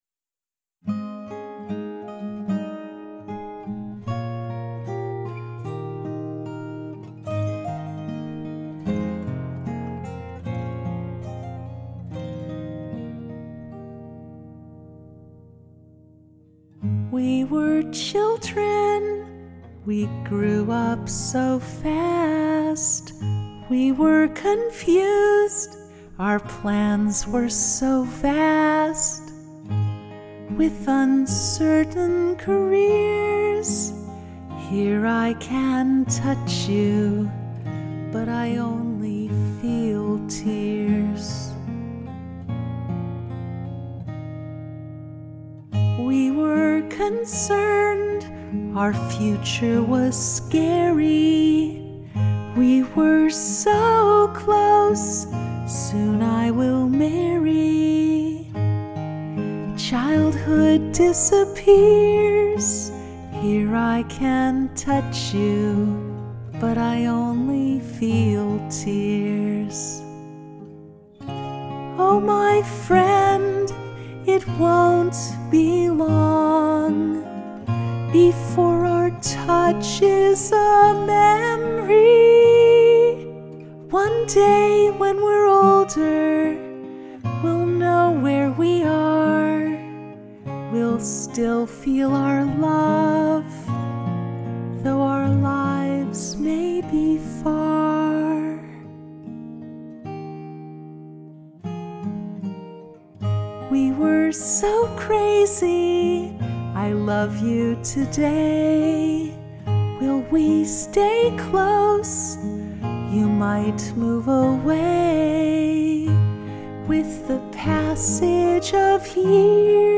I share my most recent one named “Only Tears.” Below is the guitar mix, a vocal mix, and guitar/piano mix for this song.